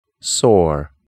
/sɔr/